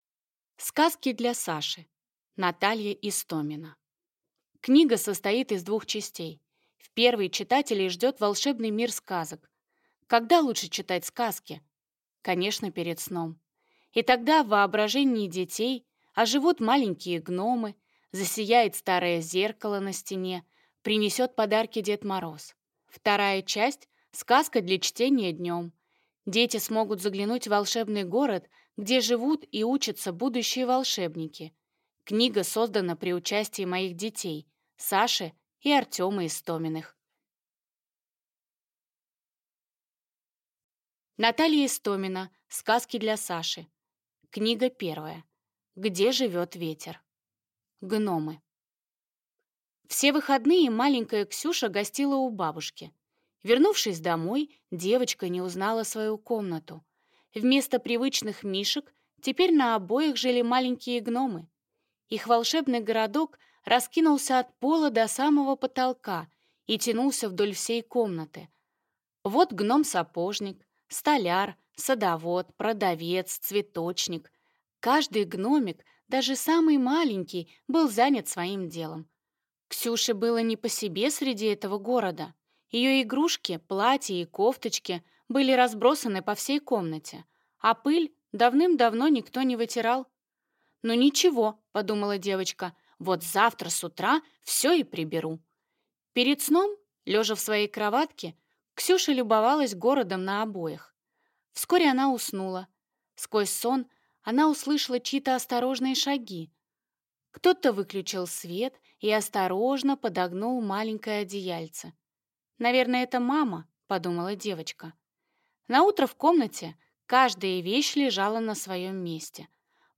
Аудиокнига Сказки для Саши | Библиотека аудиокниг